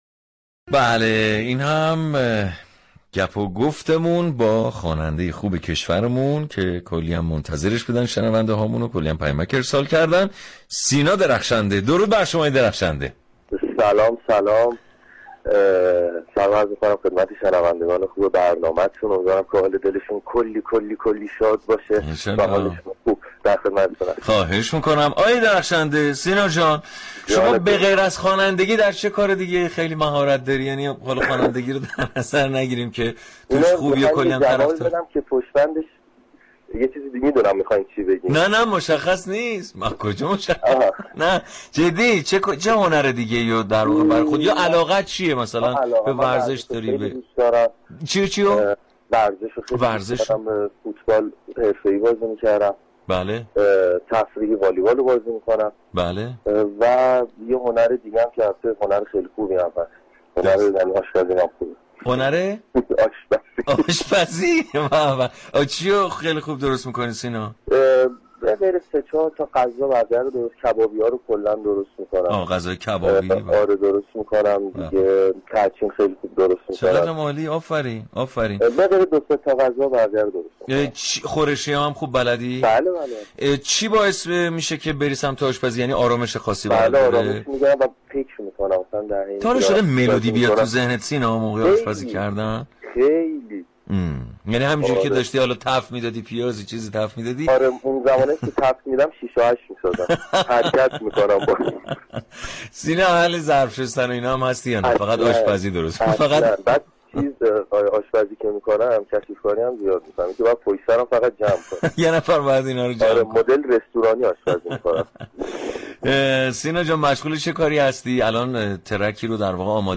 به گزارش روابط عمومی رادیو صبا، برنامه موسیقی محورصباهنگ هر روز با پخش موسیقی های درخواستی مخاطبان و گفتگو با هنرمندان این عرصه راهی آنتن صبا می‌شود.